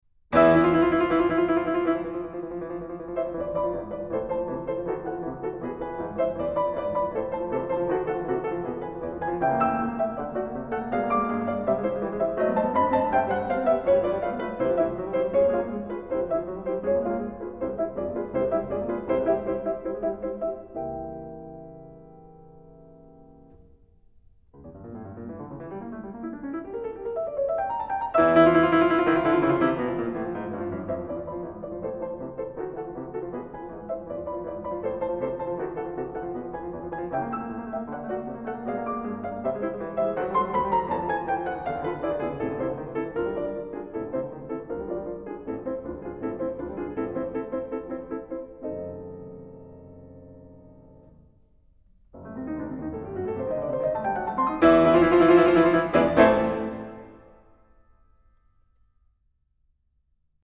Juegos de Niños Op.22 (versión original para piano a cuatro manos)
Música clásica